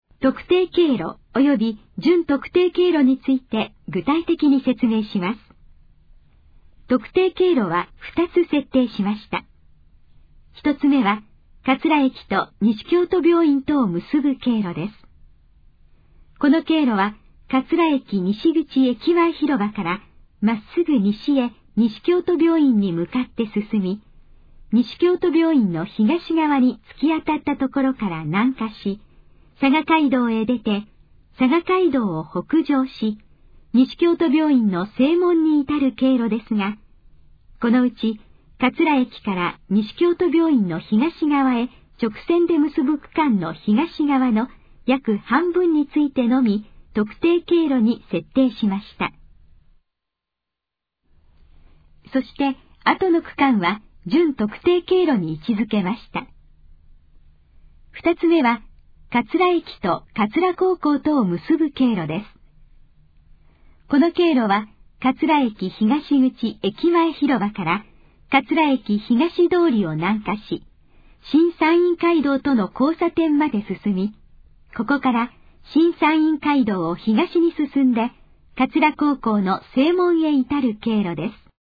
以下の項目の要約を音声で読み上げます。
ナレーション再生 約164KB